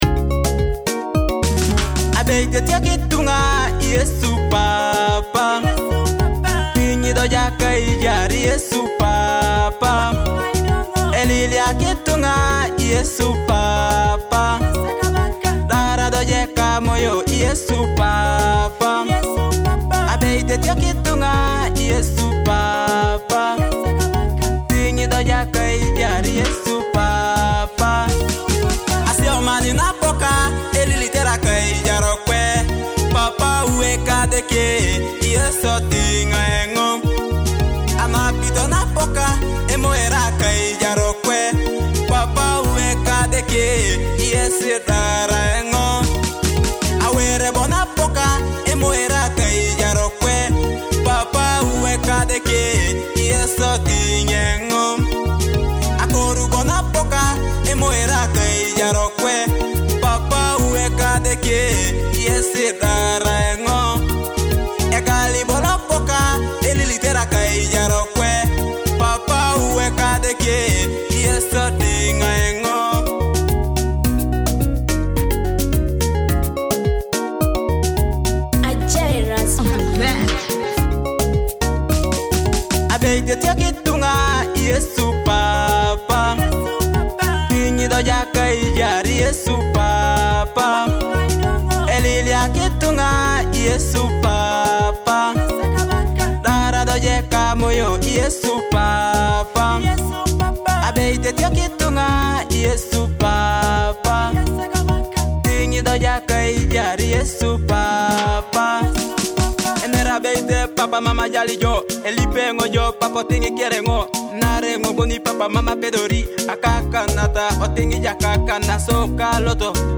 deep and soul-touching Teso gospel song